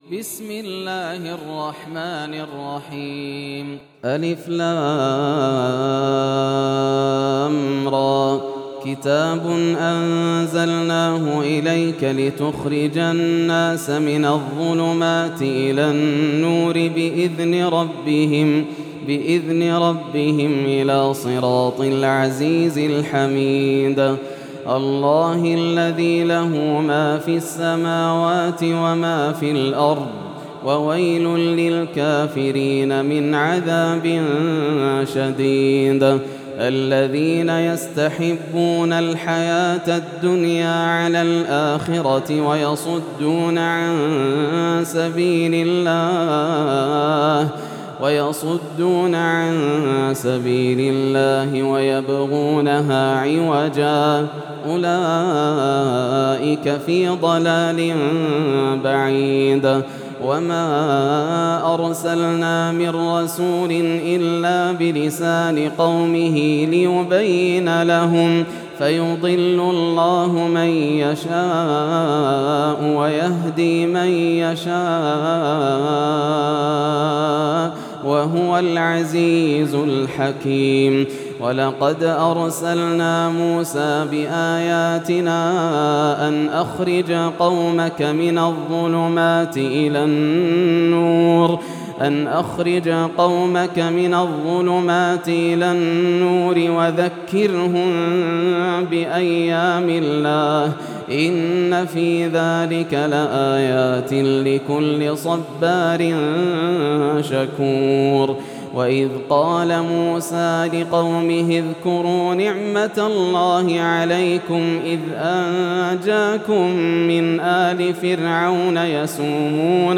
سورة إبراهيم > السور المكتملة > رمضان 1433 هـ > التراويح - تلاوات ياسر الدوسري